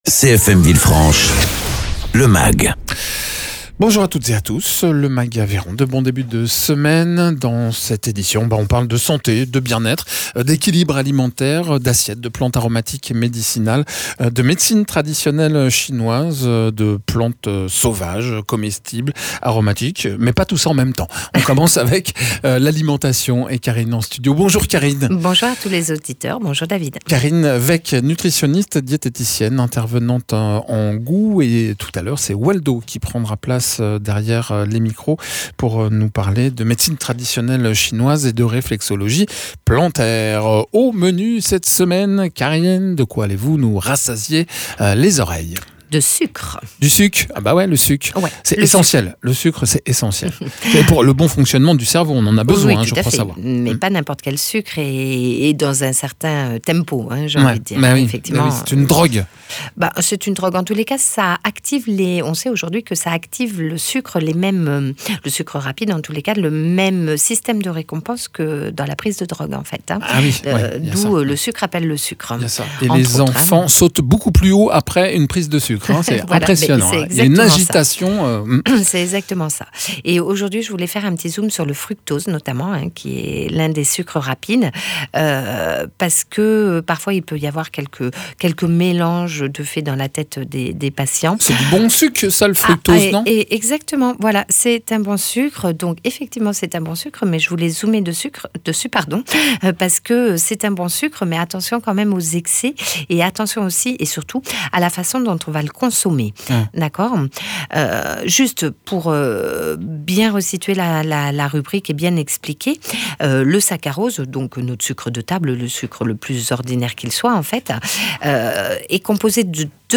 nutritionniste diététicienne
praticien en réflexologie plantaire et Médecine Traditionnelle Chinoise